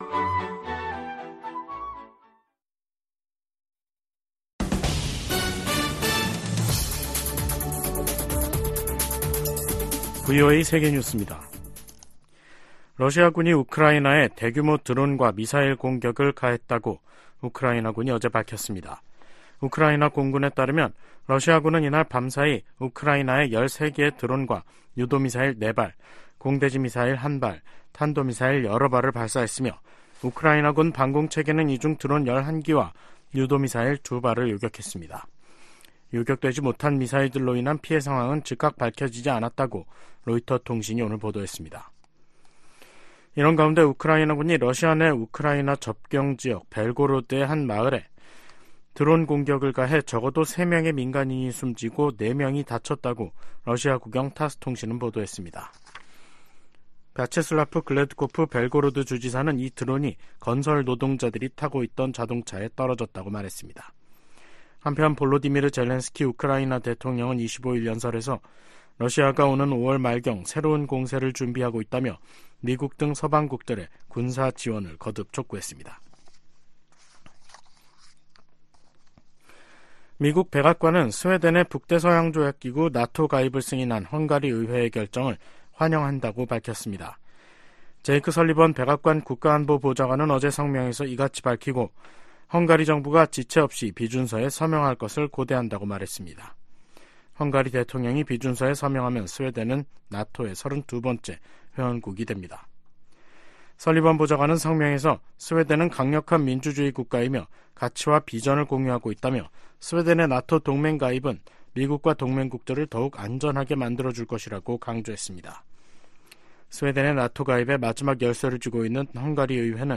VOA 한국어 간판 뉴스 프로그램 '뉴스 투데이', 2024년 2월 27일 2부 방송입니다. 제네바 유엔 군축회의 첫날 주요국들이 한목소리로 북한의 핵과 미사일 개발을 강력히 비판했습니다. 북한이 러시아에 수백만 발의 포탄을 지원했고 러시아는 대북 식량 지원에 나서 북한 내 식량 가격이 안정세를 보이고 있다고 신원식 한국 국방부 장관이 밝혔습니다. 미국 상무부는 미국산 제품이 북한 미사일에서 발견된데 우려하고, 강력 단속 의지를 밝혔습니다.